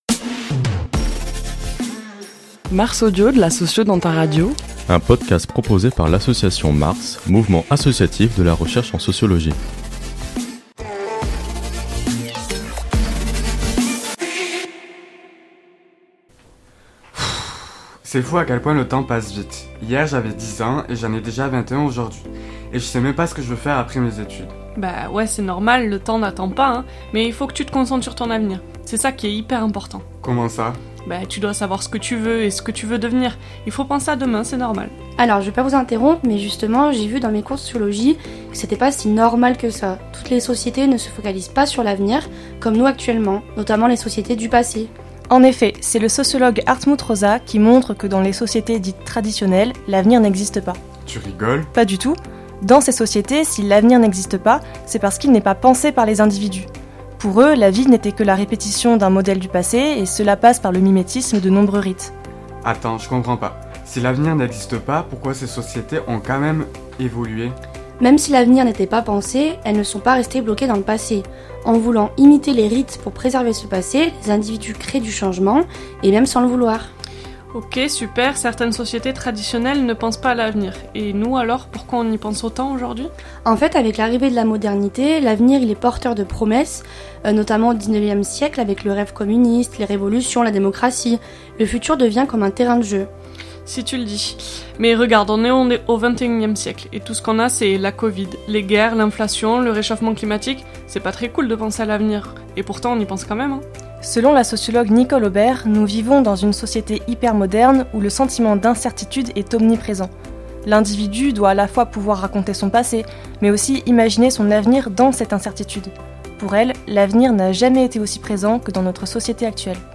Ce mois-ci, MARS Audio vous invite à découvrir le Temps à travers un court dialogue fictif.